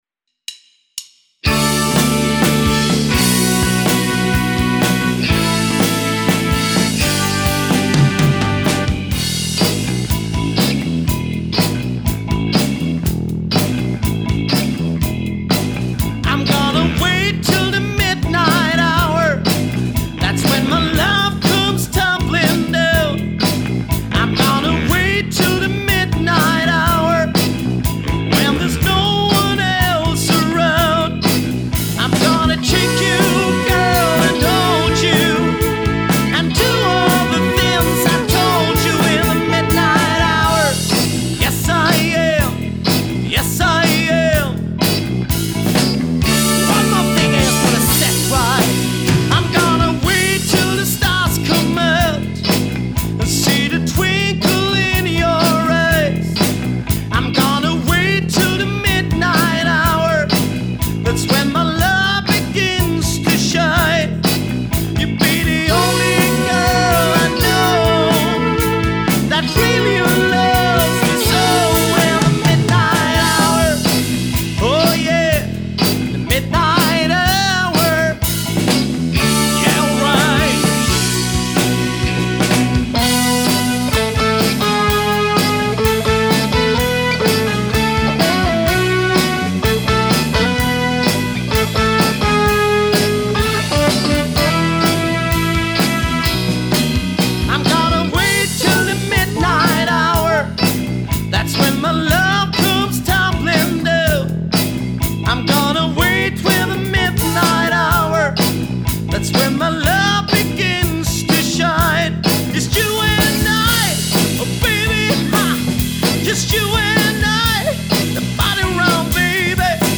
• Coverband
• Soul/Disco